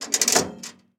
Звуки турникета
Звук вращающегося турникета